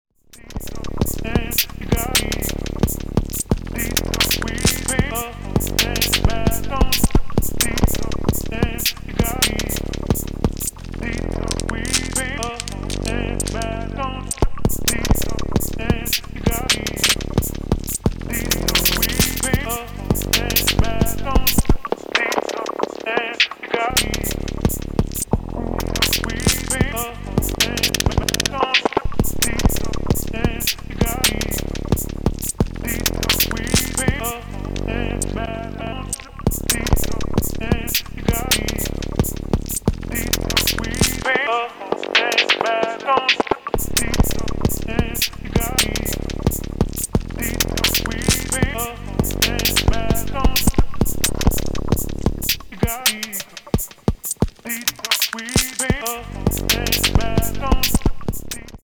made this with brandy acapella step-sample chops, a kick and hat from the syntakt, and a super short (End position set to 1) single cycle waveform from a syntakt bass sound i tried to sample but it ended up sounding better when i looped and shortened it. it also sounded CRAZY when i added some audio rate cycling eg but i didnt use that here. then also some things like crushing dry easy mac with a bowl and the sound of me squishing the insides of a pumpkin while wearing latex gloves
just realized i actually didnt use the granular engine for once. crazy
this is trippy, loving all the organic sounds